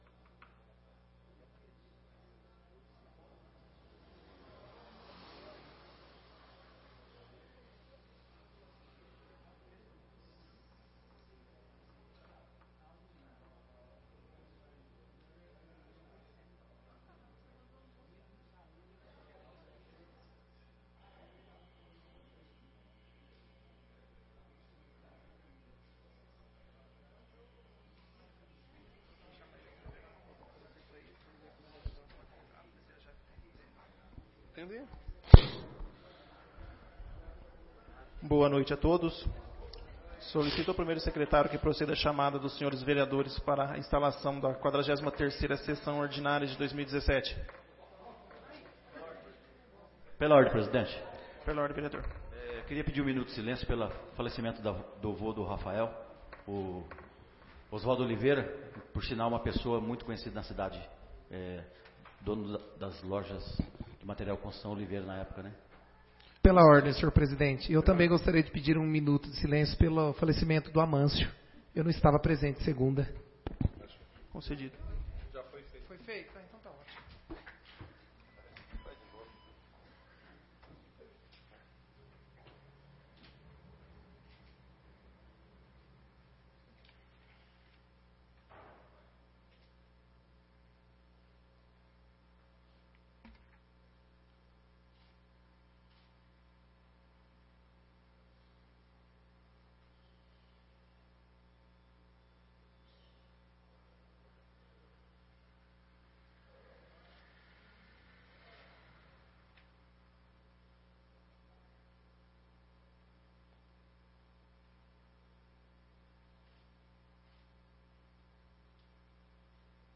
43ª Sessão Ordinária de 2017